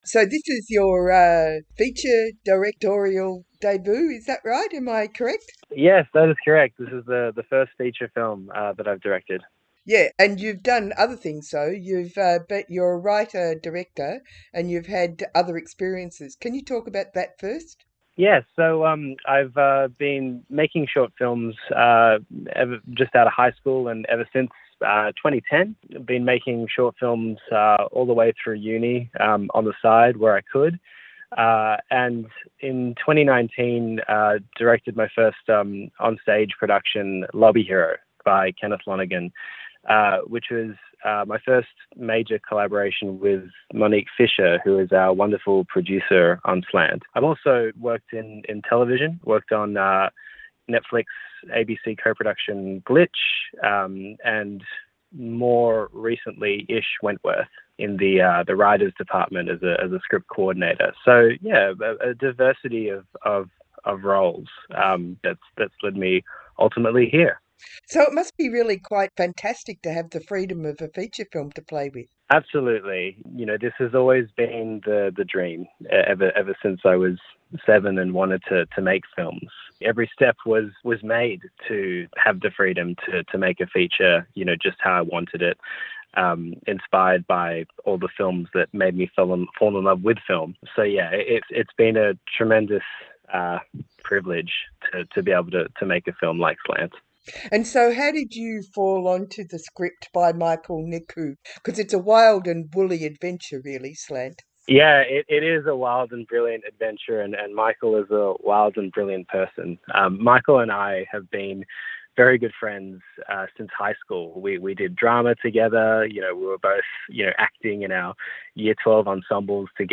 Your half hour of local film news, conversations with film makers and explorations into how they bring their ideas to life on screen.